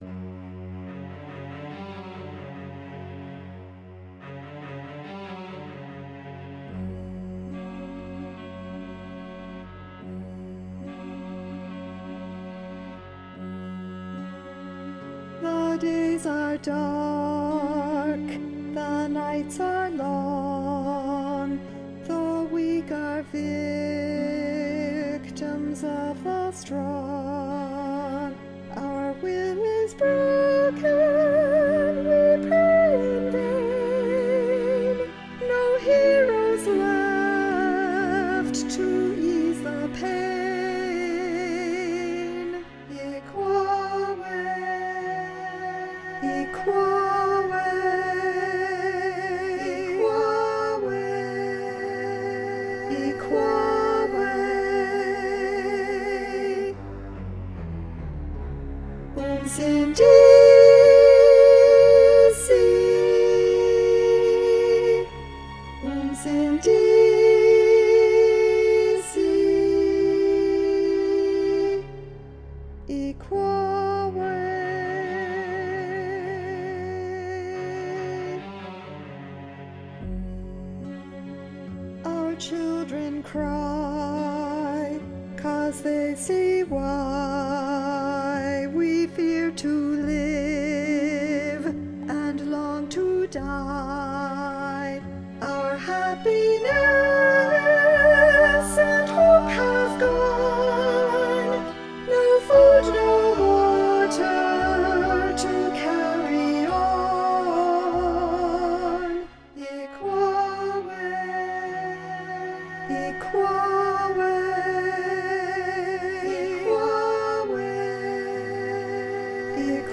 Inspired by the music of South Africa, Rain Dance is a 75-minute, humorous, entirely-sung political drama inspired by Tish Farrell's story, "The Hare Who Would Not Be King".